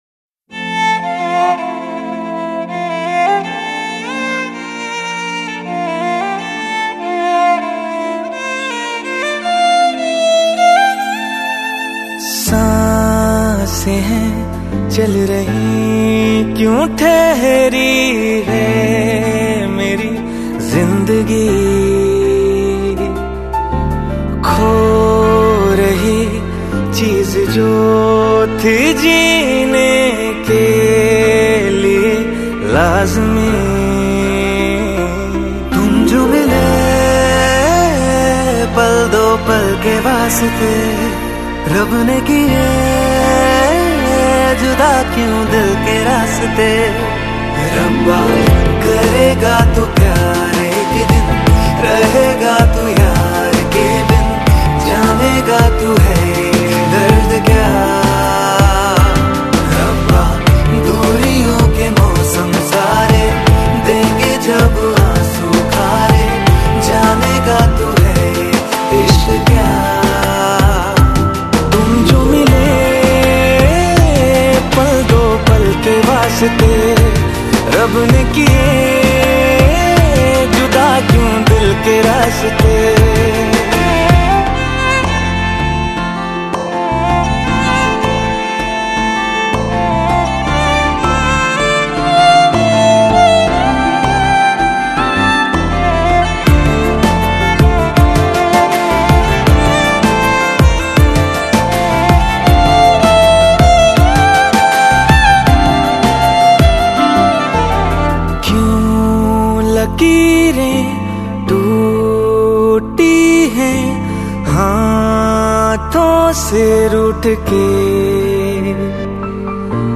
Unplugged